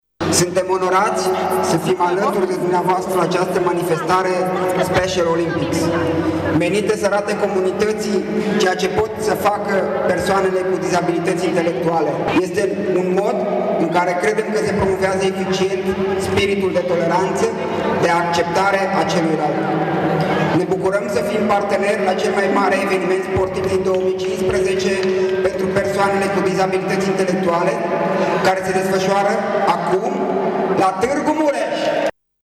Ieri, în Sala Polivalentă din Tîrgu-Mureș a avut loc festivitatea de inaugurare a unei noi ediții a Special Olympics România, competiție rezervată persoanelor cu dizabilitați intelectuale.